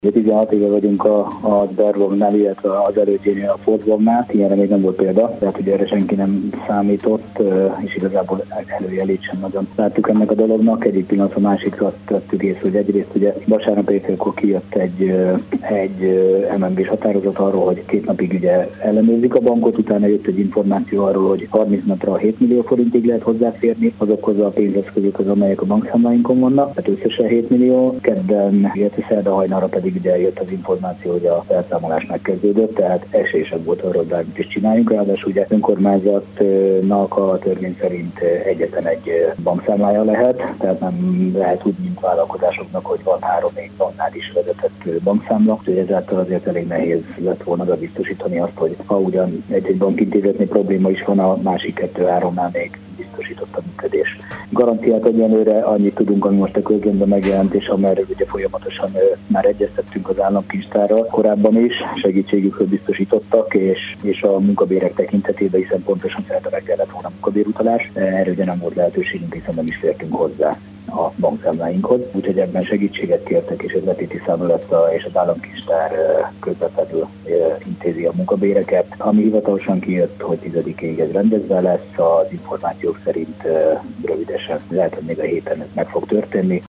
Nagy János polgármester azt mondta, az Önkormányzat a kialakult helyzetről a felettes állami szervekkel folyamatosan egyeztet, mindent megtesznek annak érdekében, hogy a település zavartalan pénzügyi működését biztosítsák.